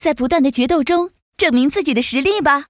文件 文件历史 文件用途 全域文件用途 Choboong_amb_01.ogg （Ogg Vorbis声音文件，长度0.0秒，0 bps，文件大小：35 KB） 源地址:游戏语音 文件历史 点击某个日期/时间查看对应时刻的文件。